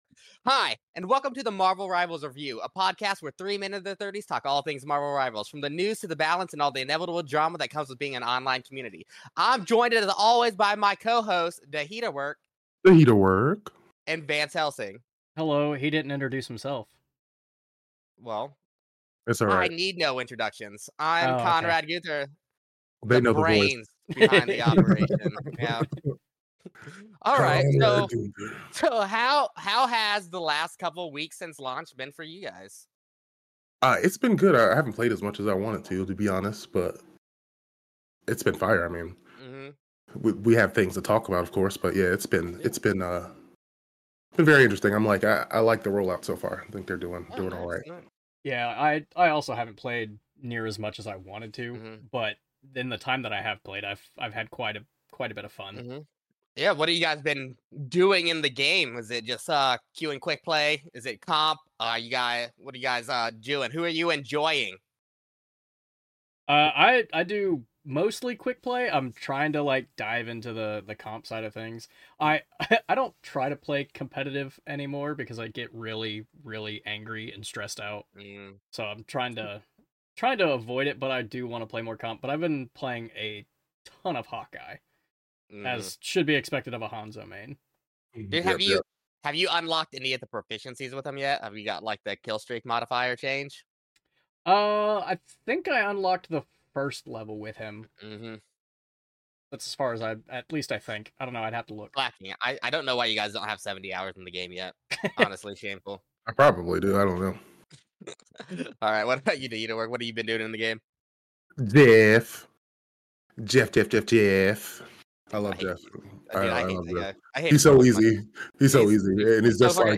Audio is fixed! Three men in their thirties talk all things Marvel Rival Beta!